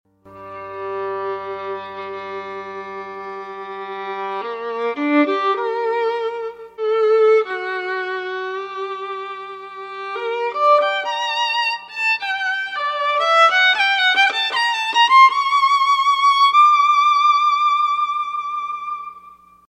All FAT HEAD SAMPLES ARE RECORDED WITH A  STOCK TRANSFORMER
FAT HEAD Violin:
violin.mp3